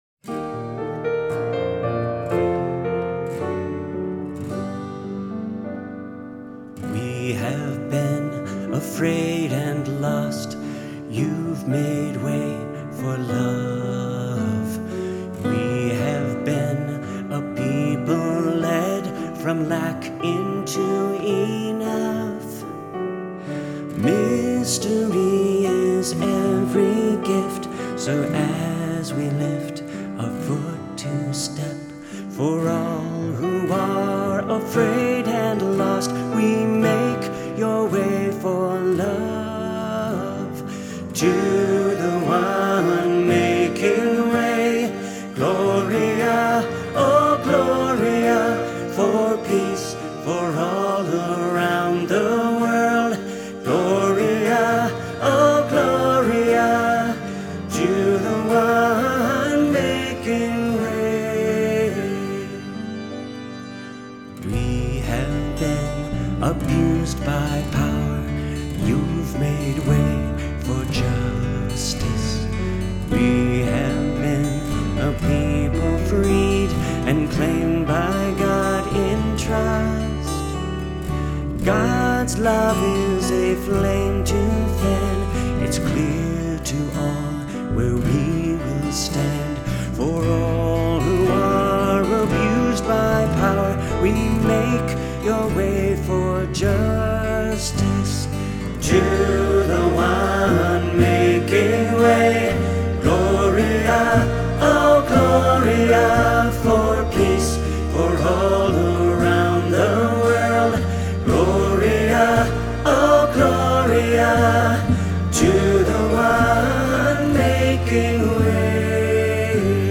This song works well with organ, piano or band ensemble.